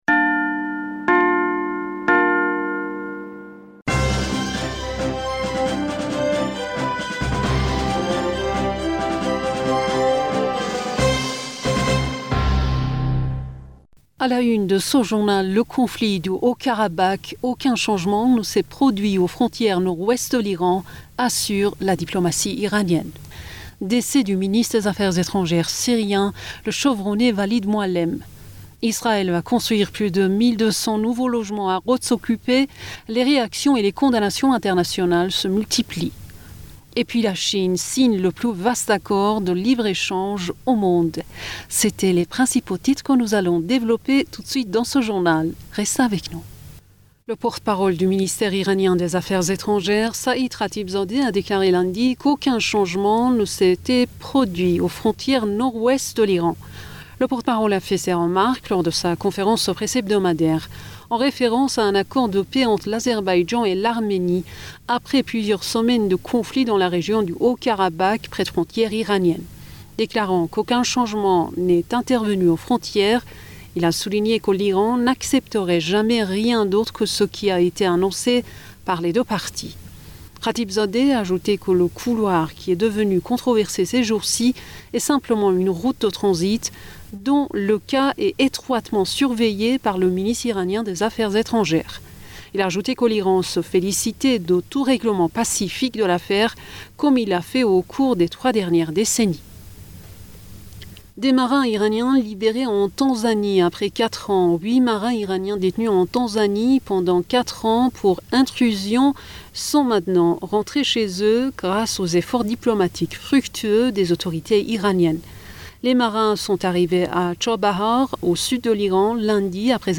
Bulletin d'informationd u 16 November 2020